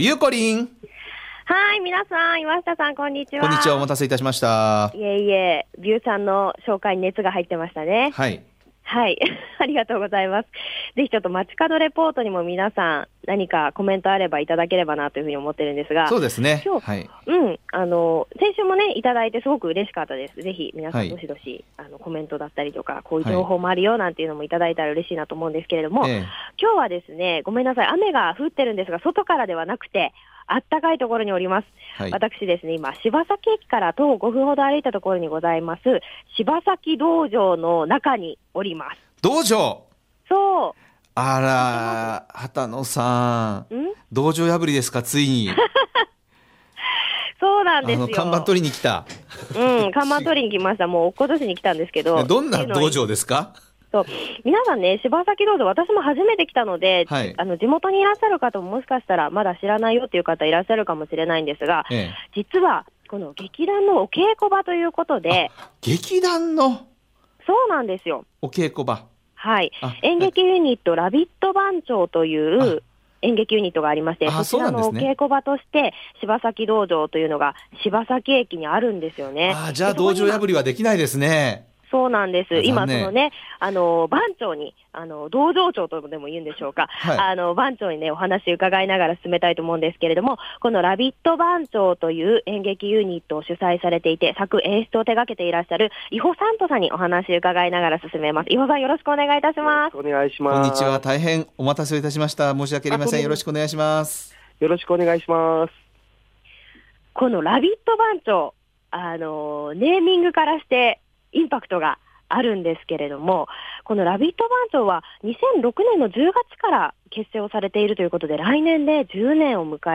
今日のびゅーサン、街角レポートは…！ 柴崎駅から徒歩5分にある、劇団ユニット「ラビット番長」のお稽古場、「柴崎道場」からお届けしましたよ～♪
中に入るとザ・お芝居のお稽古場という、熱気漂う雰囲気の中からレポートいたしました☆